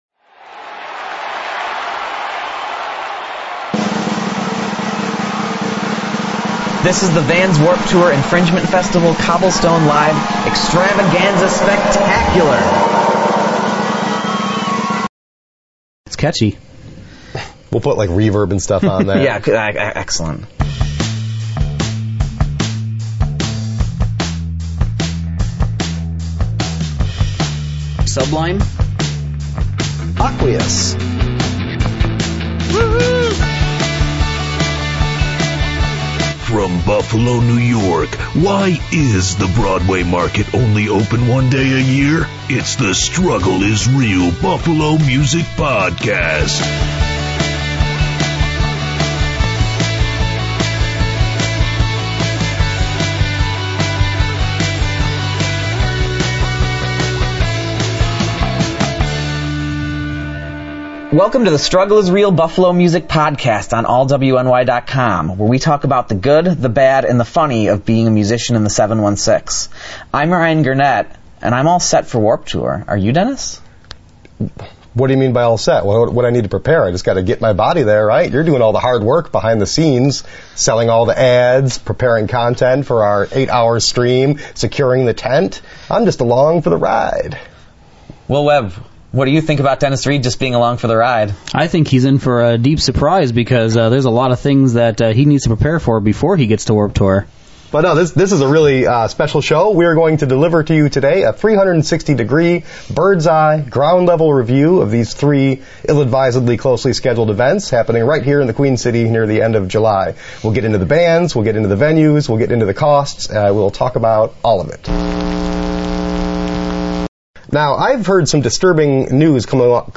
Musicians
MUSIC